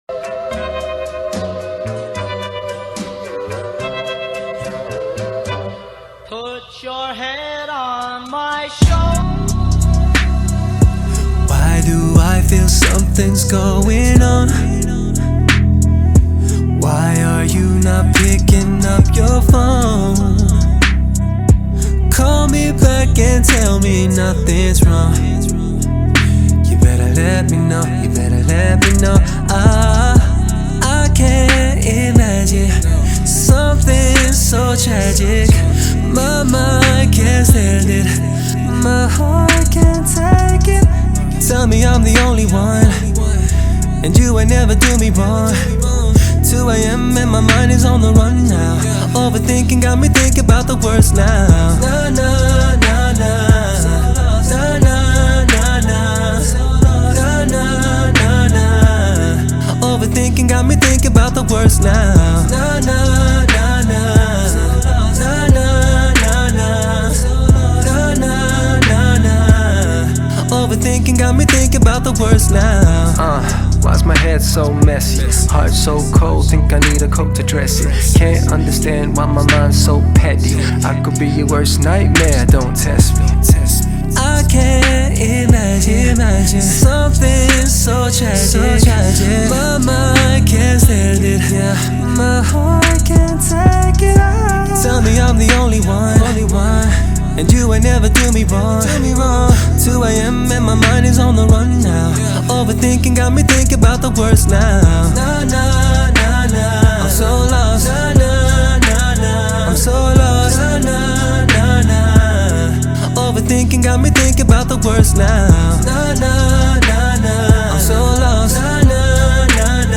это энергичная поп-музыка с элементами R&B